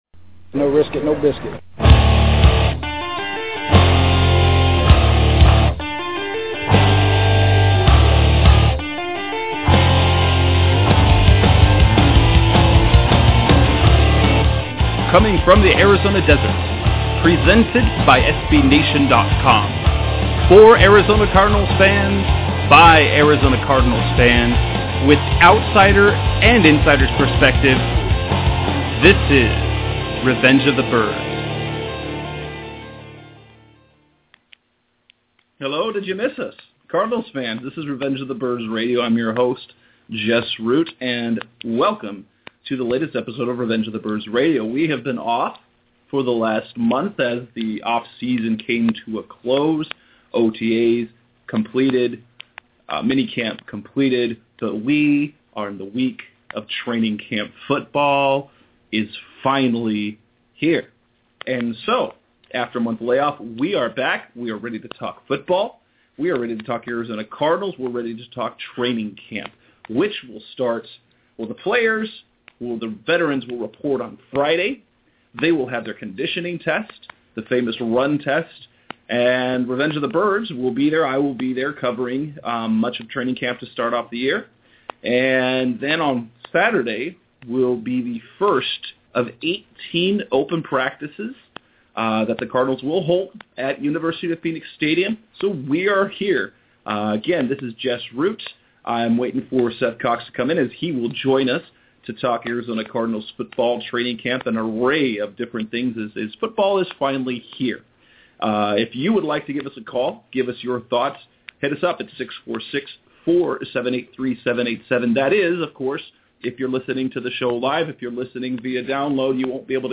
The radio show is back and the NFL is back!